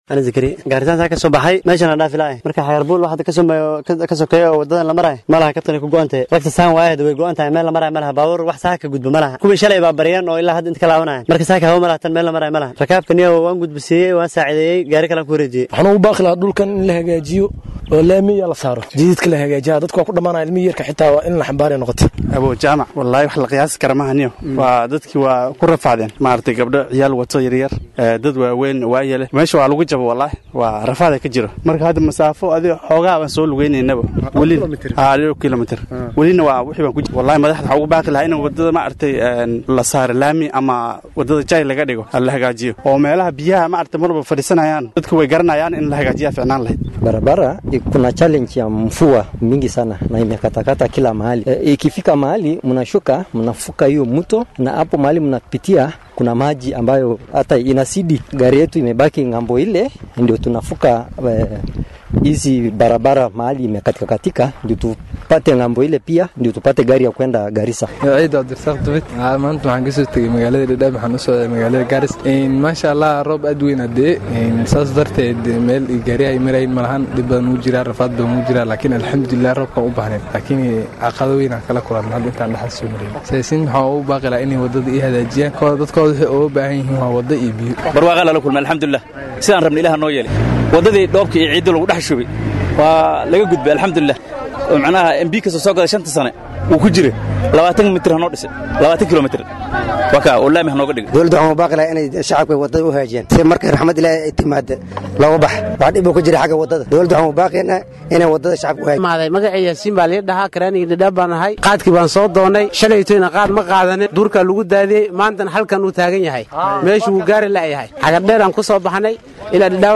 Cabashada-Shacabka-Dadaab.mp3